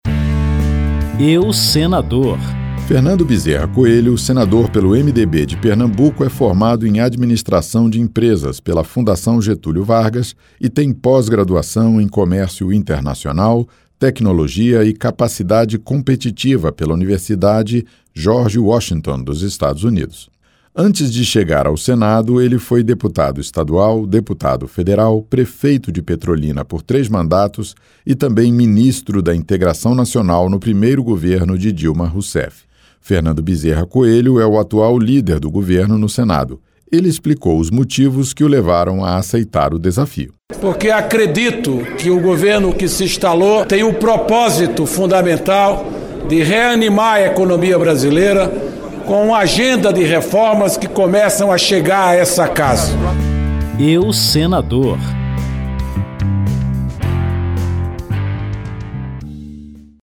Um perfil resumido de cada senador em exercício na atual Legislatura. Dados biográficos, trajetória na política e prioridades do mandato. Boletins de 1 minuto, veiculados ao longo da programação.